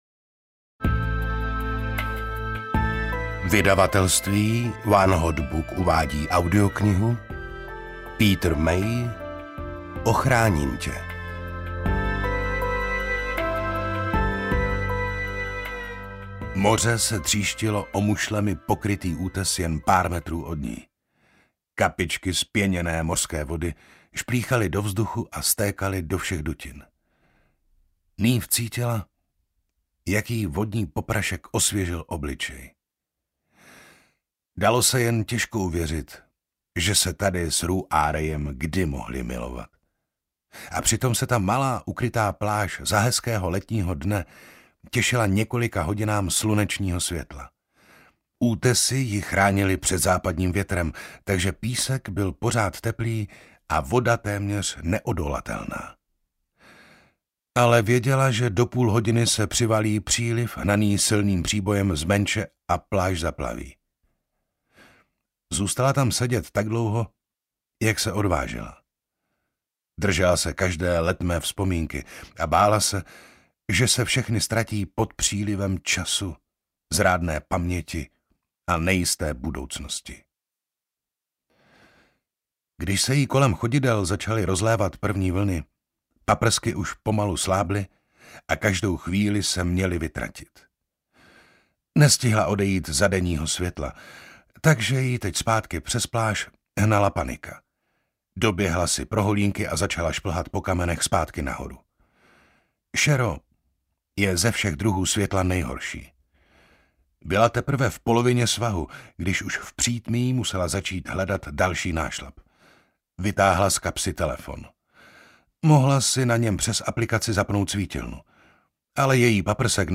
Ochráním tě audiokniha
Ukázka z knihy